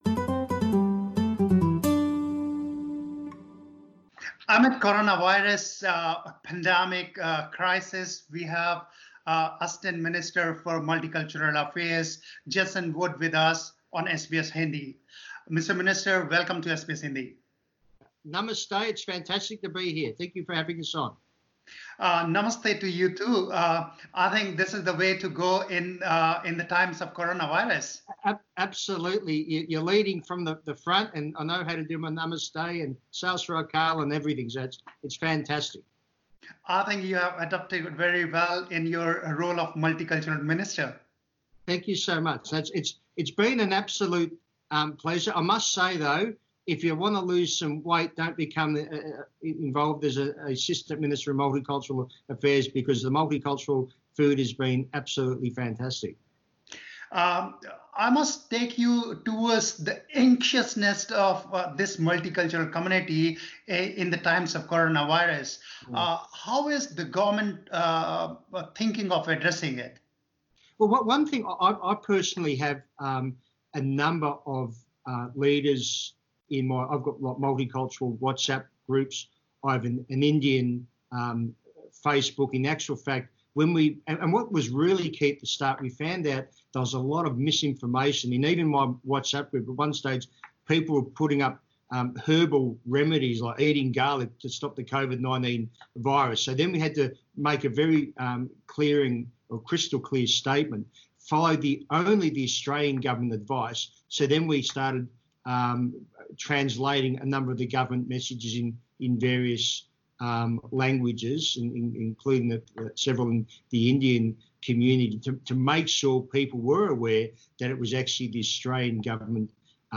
Minister Jason Wood in an exclusive interview with SBS Hindi has reassured parents of international students that their children are safe in Australia.